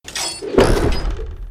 catapult_launch.ogg